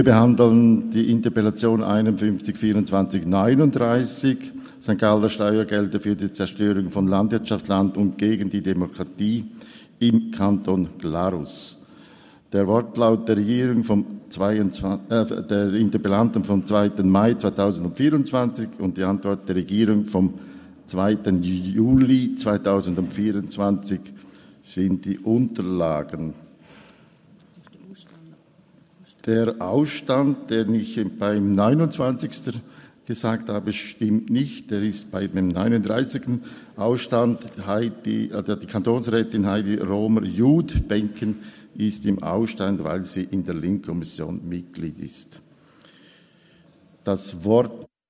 18.9.2024Wortmeldung
Session des Kantonsrates vom 16. bis 18. September 2024, Herbstsession